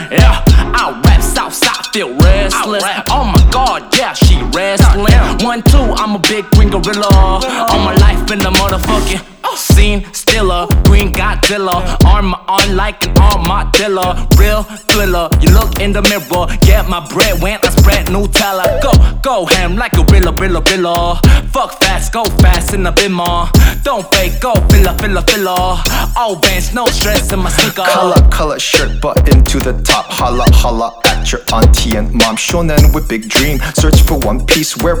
K-Pop Pop Hip-Hop Rap
Жанр: Хип-Хоп / Рэп / Поп музыка